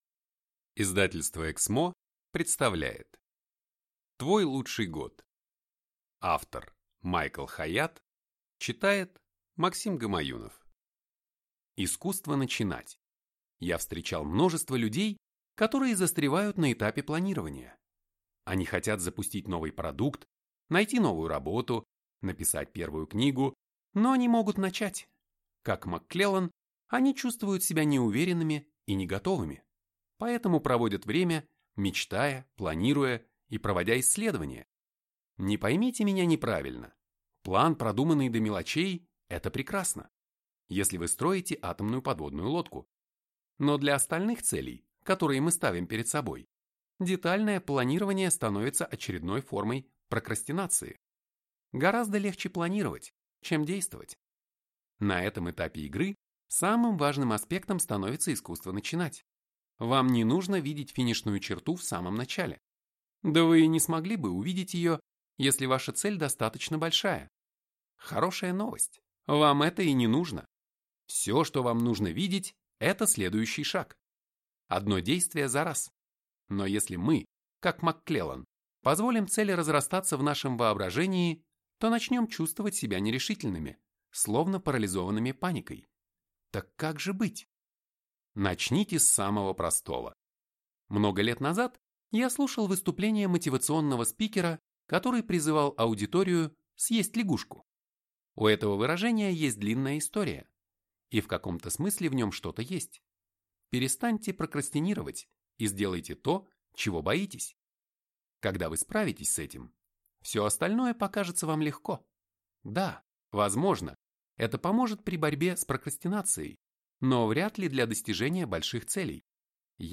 Аудиокнига Твой лучший год. 12 невероятных месяцев, которые изменят вашу жизнь | Библиотека аудиокниг